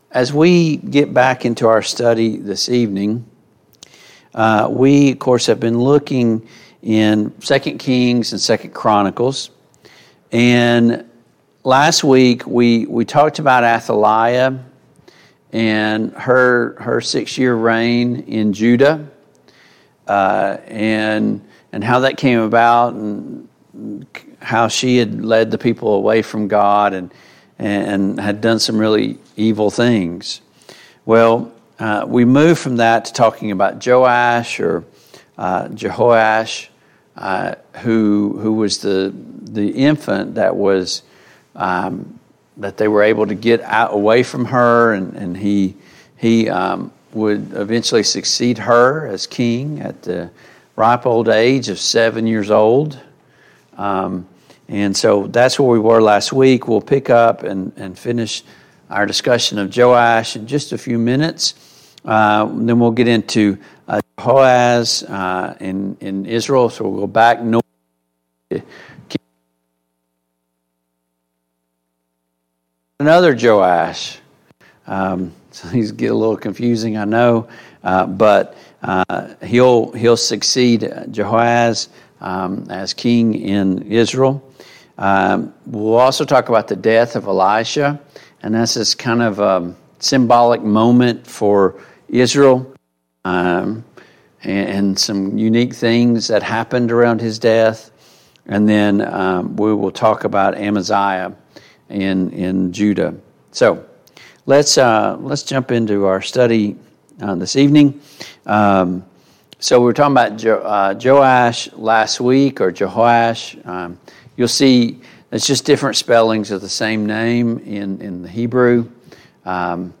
The Kings of Israel and Judah Passage: 2 Chronicles 24, 2 Chronicles 25, 2 Kings 12 Service Type: Mid-Week Bible Study Download Files Notes « 2.